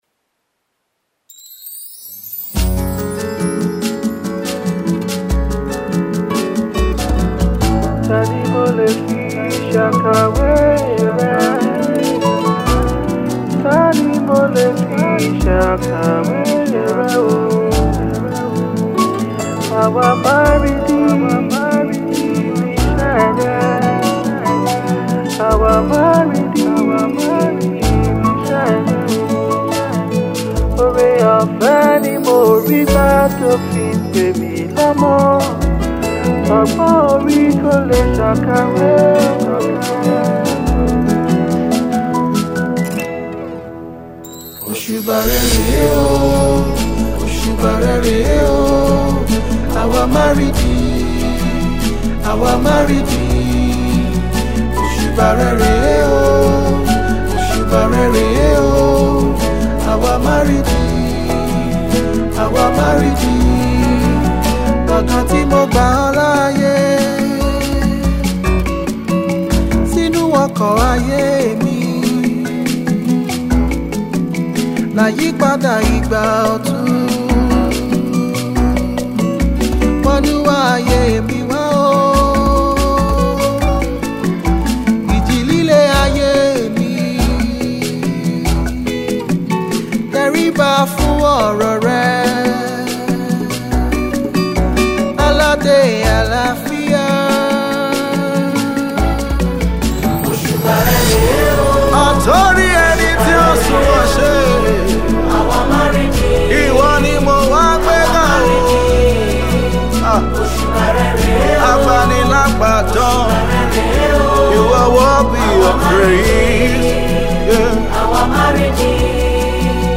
Gospel Artiste